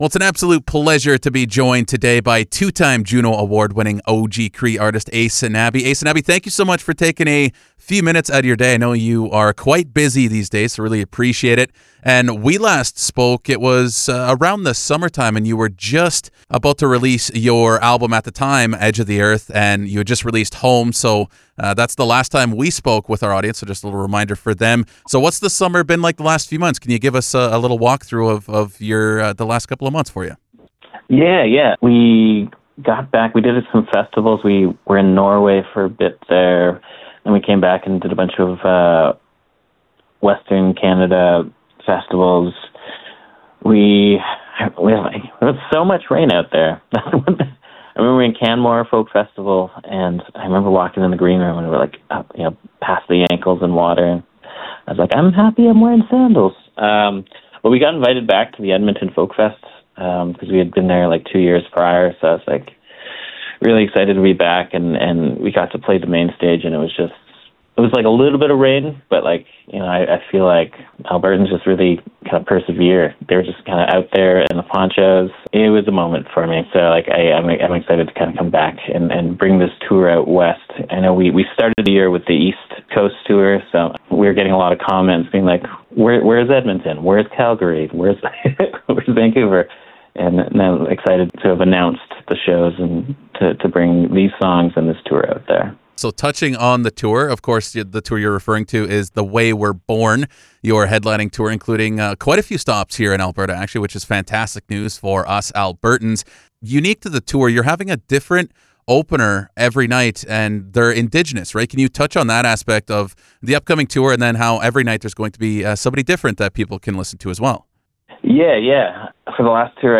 aysanabee-interview-october-22-full-cut-version.mp3